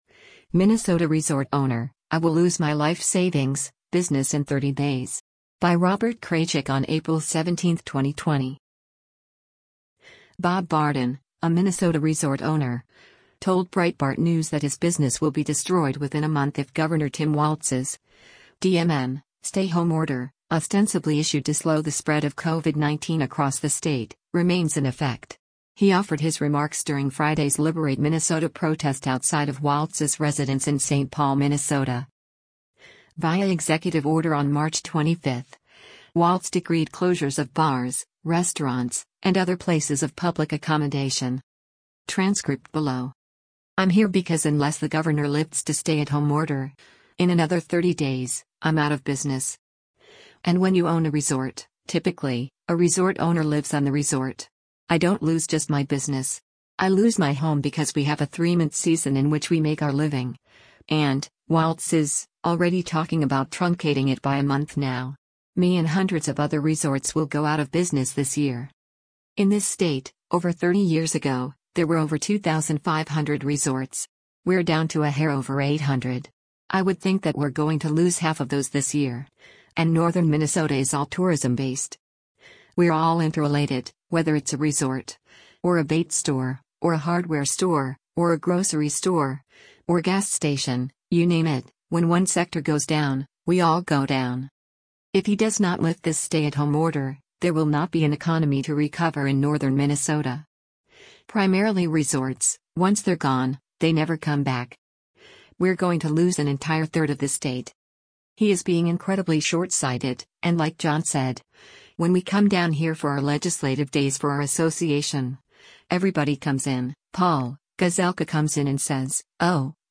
He offered his remarks during Friday’s “Liberate Minnesota” protest outside of Walz’s residence in Saint Paul, MN.